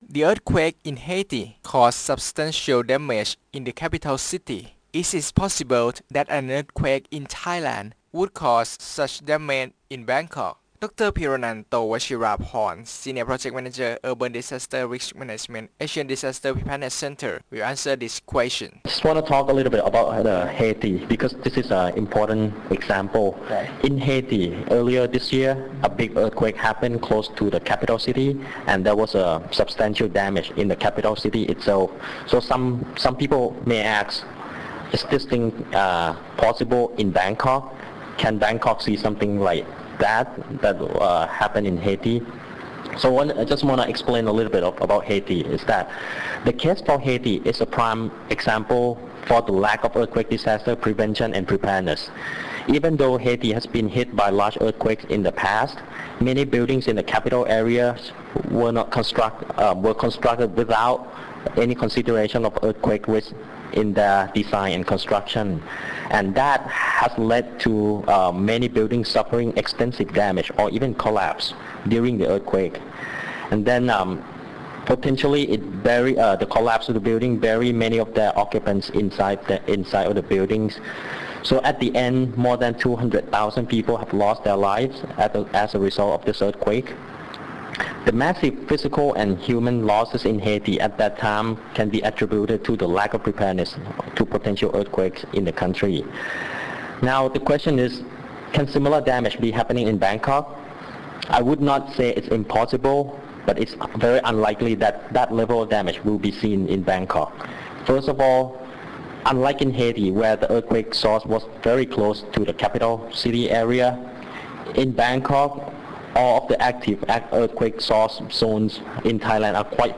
The recorded interviews have been aired through FM. 88
Interview_Radio Thailand_9June.mp3